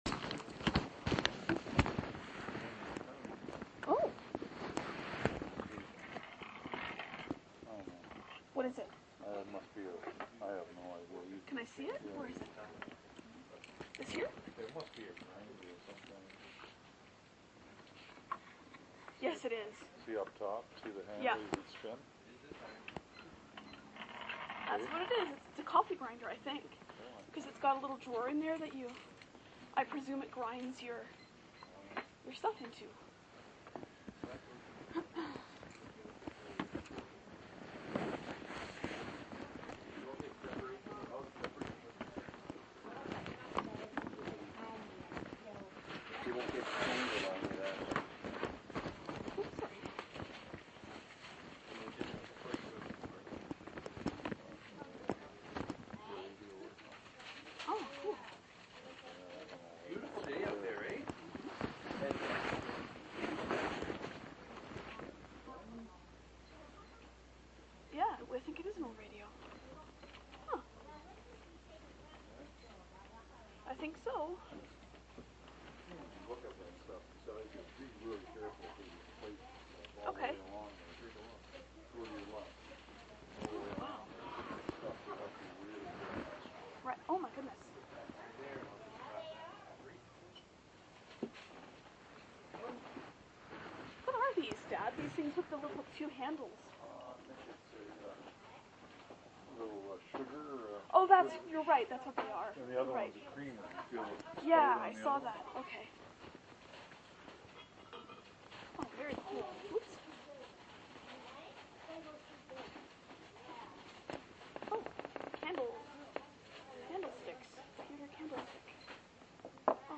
Thrift Shop Ambiance
Sorry for the poor sound quality and the rustling of the mic; the Olympus was in my purse.